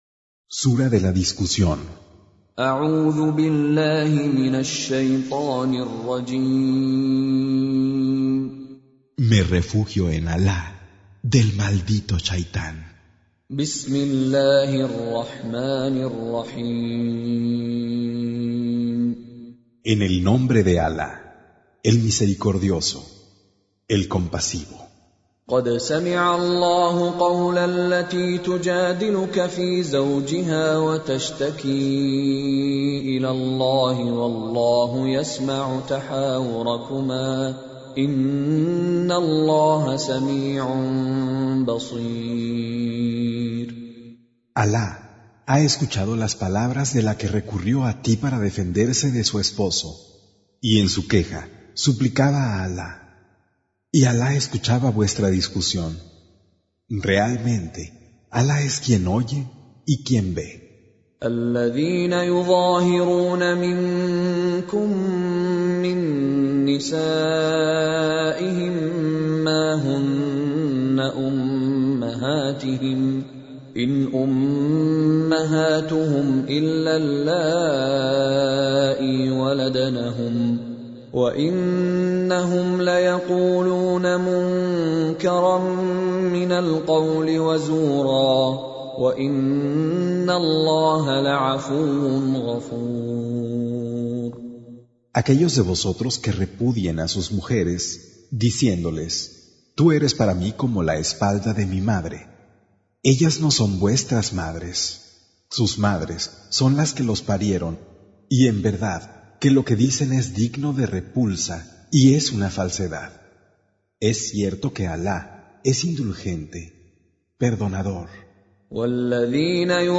58. Surah Al-Muj�dilah سورة المجادلة Audio Quran Tarjuman Translation Recitation Tarjumah Transliteration Home Of Spanish Translation With Mishary Alafasi :: Traducción al español del Sagrado Corán - Con Reciter Mishary Alafasi - Audio Quran Listing Reciters, Qur'an Audio,
Surah Sequence تتابع السورة Download Surah حمّل السورة Reciting Mutarjamah Translation Audio for 58.